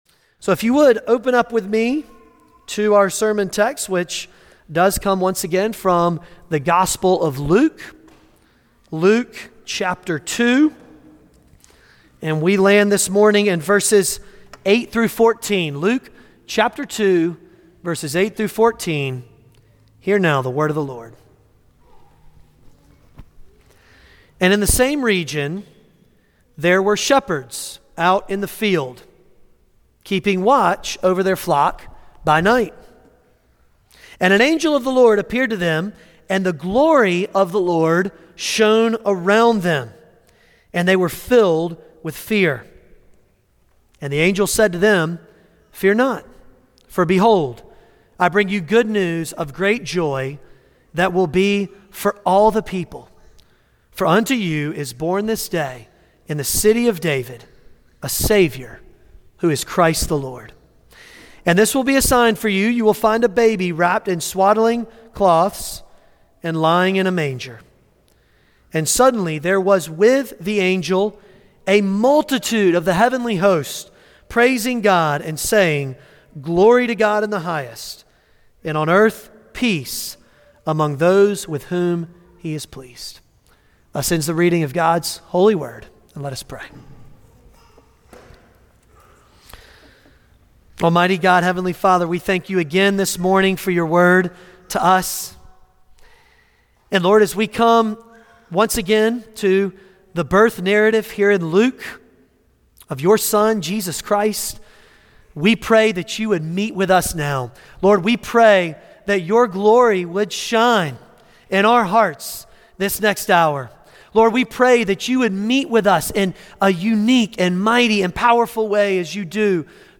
Passage: Luke 2:8-14 Service Type: Sunday Morning